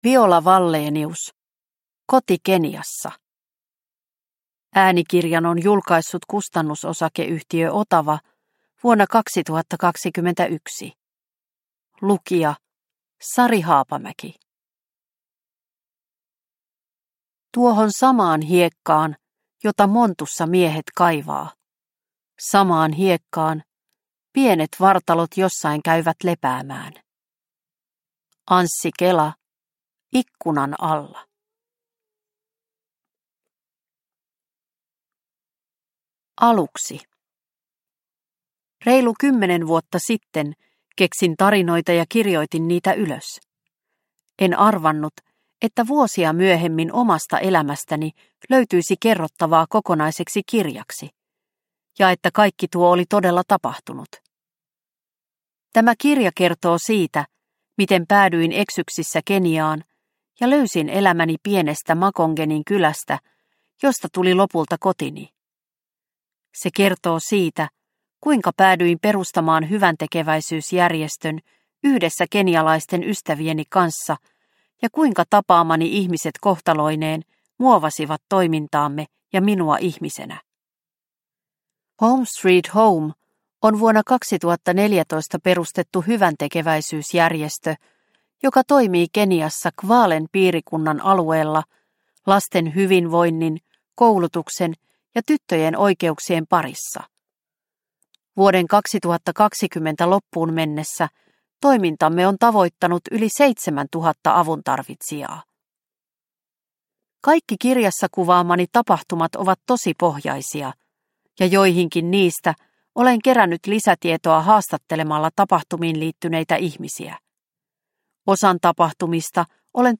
Koti Keniassa – Ljudbok – Laddas ner